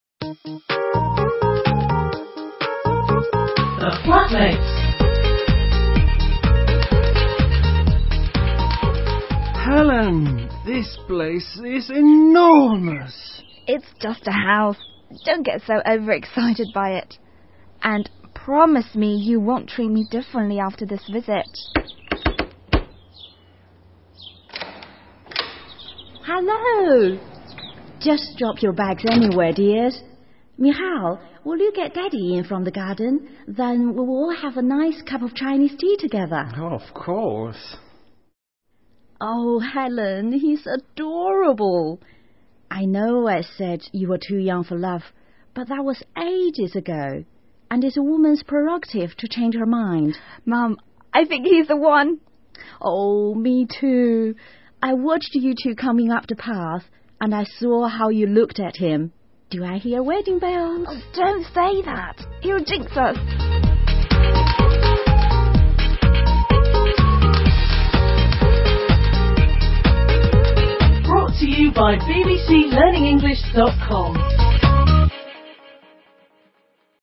生活英语对话 Episode 54: A mother-daughter chat 听力文件下载—在线英语听力室